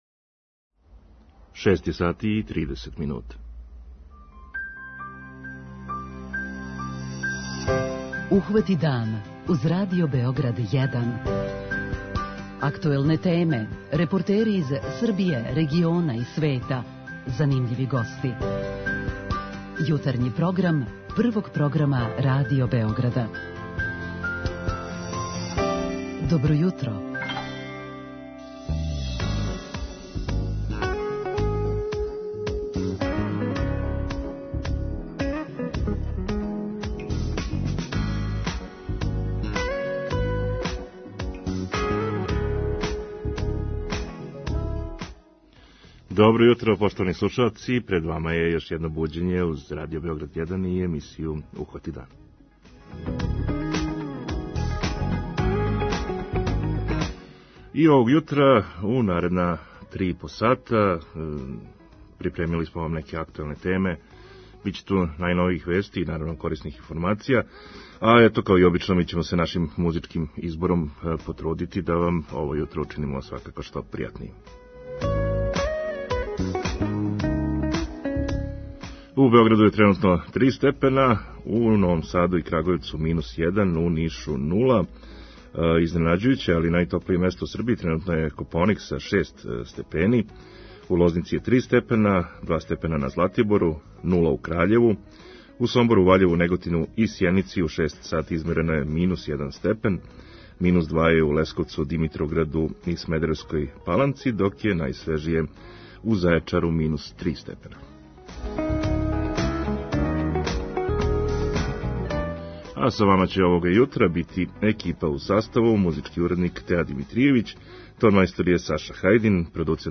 Овог јутра посетићемо екипу мобилног мамографа како бисмо сазнали колико је интересовање и како протичу заказани прегледи. преузми : 37.80 MB Ухвати дан Autor: Група аутора Јутарњи програм Радио Београда 1!